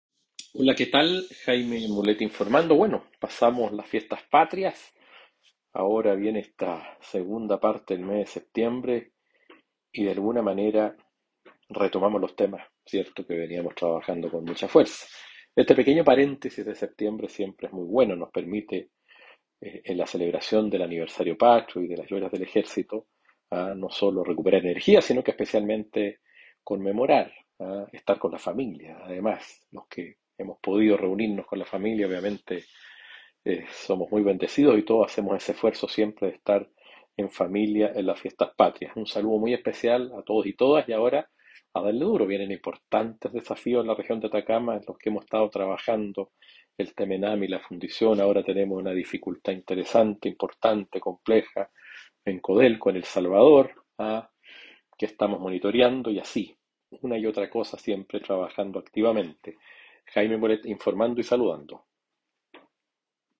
Audio Diputado Jaime Mulet, hace referencia al trabajo parlamentario que continúa, luego de la celebración de fiestas patrias.